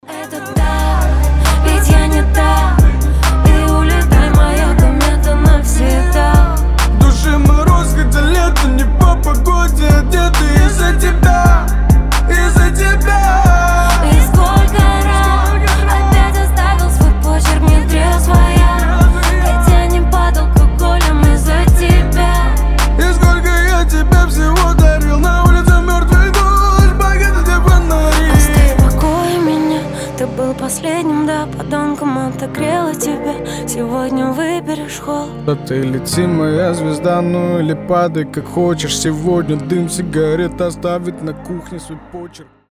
• Качество: 320, Stereo
лирика
грустные
дуэт